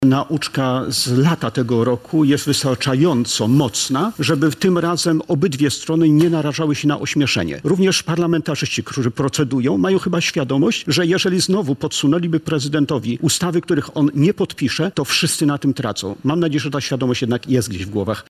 w „Salonie Dziennikarskim” na antenie Radia Warszawa